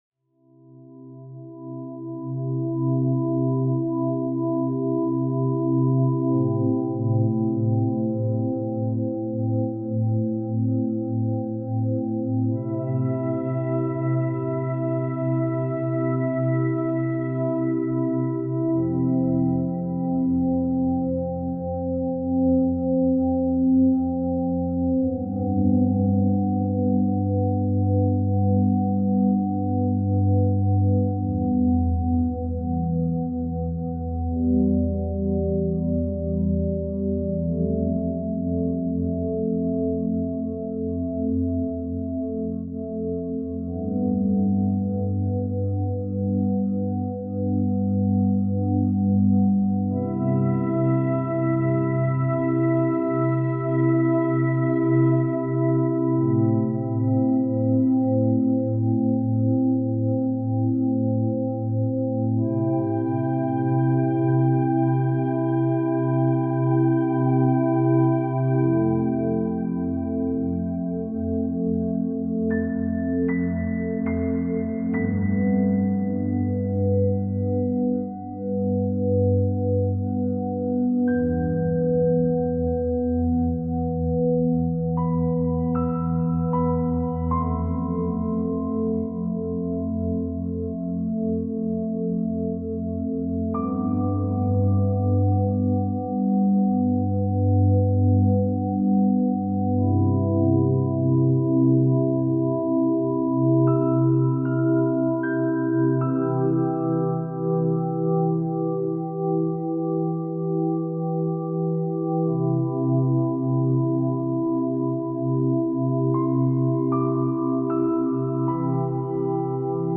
All advertisements are thoughtfully placed only at the beginning of each episode, ensuring you enjoy the complete ambient sounds journey without any interruptions. This commitment to your uninterrupted experience means no sudden advertising cuts will disturb your meditation, sleep, or relaxation sessions.